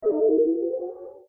Song: SFX Teleport